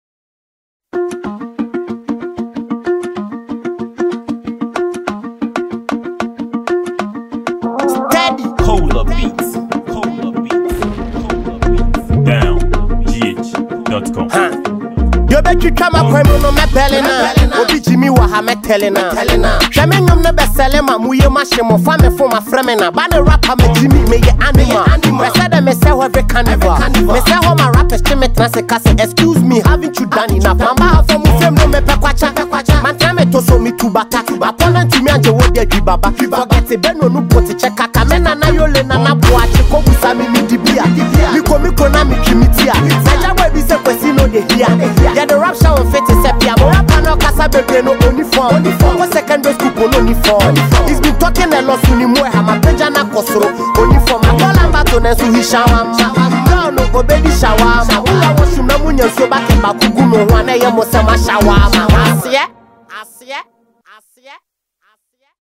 Ghanaian talented rapper and musician
a freestyle song for mp3 download.